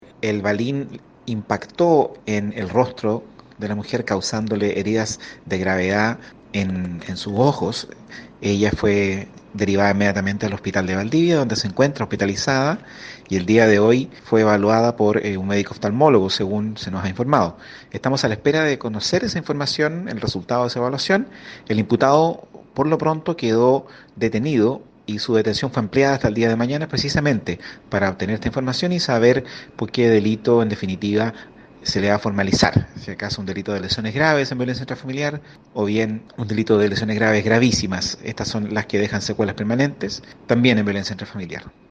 FISCAL-ALVARO-PEREZ-1-OK.mp3